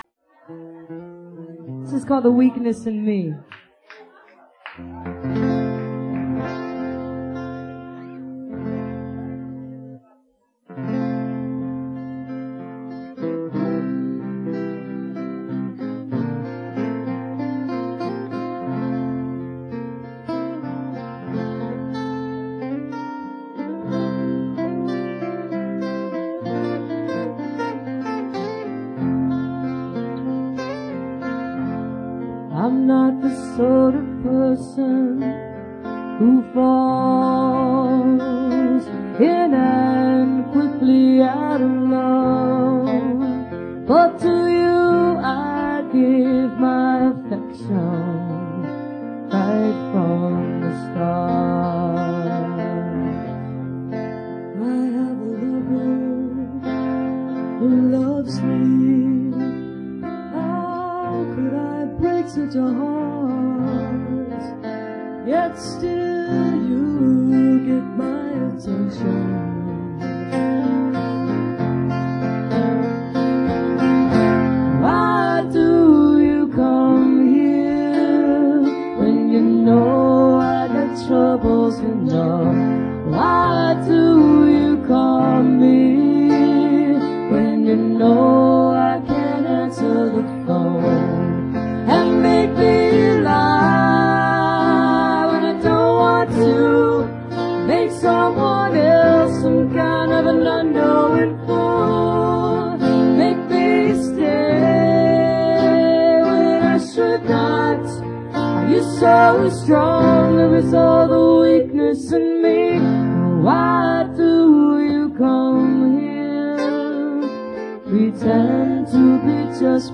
recorded at the dugout - decatur, georgia